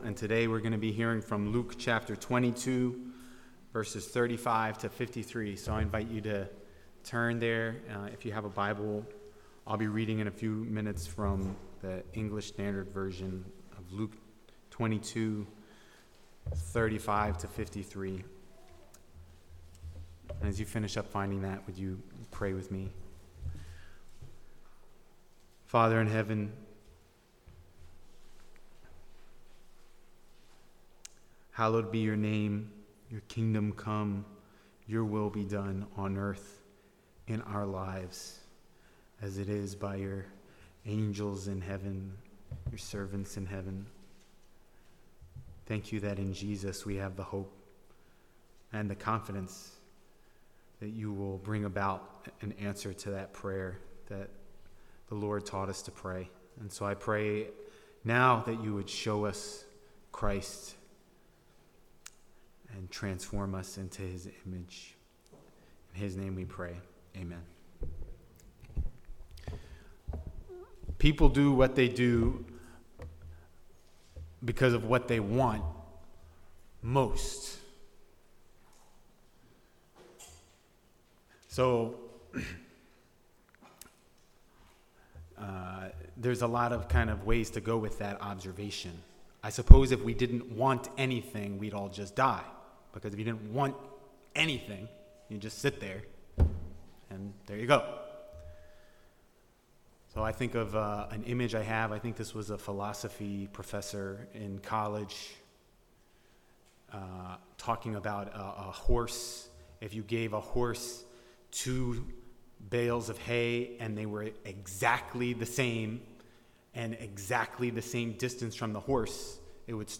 Sermons | The Bronx Household of Faith